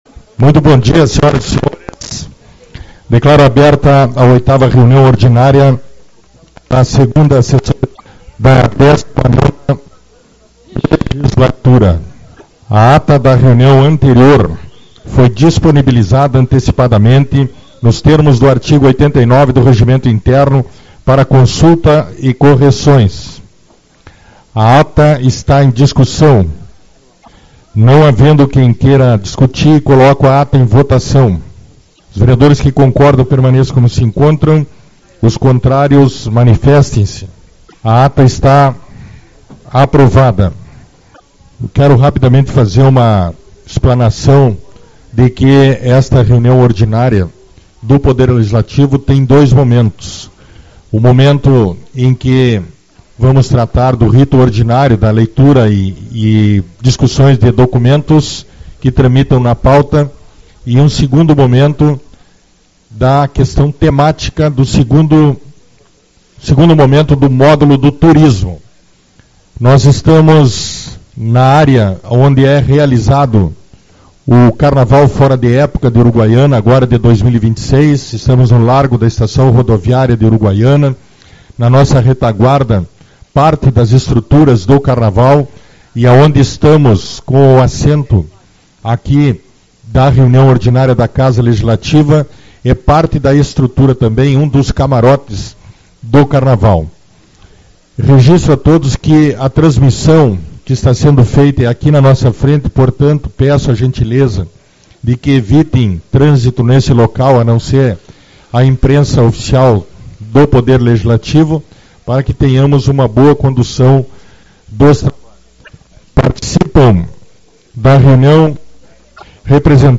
03/03 - Reunião Ordinária-Turismo-Carnaval